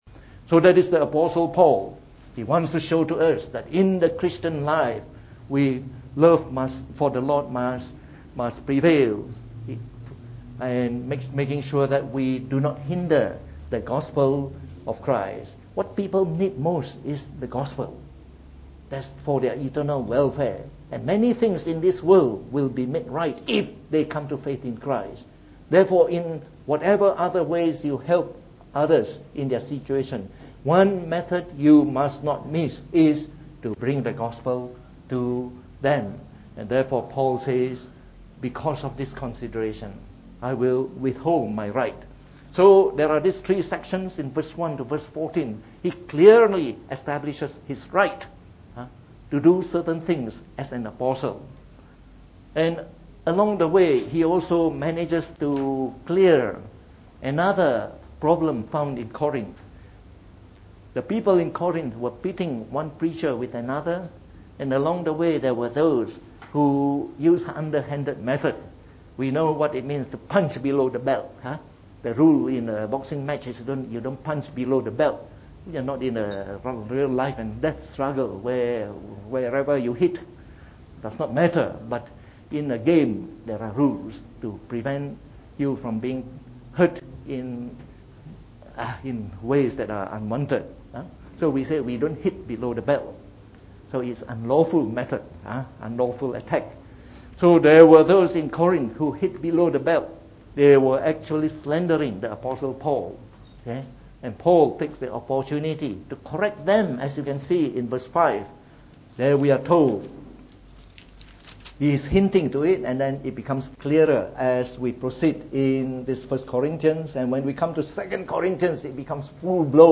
From our series on 1 Corinthians delivered in the Evening Service.
(Please note: due a computer error, the beginning of this sermon was lost).